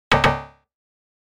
error.ogg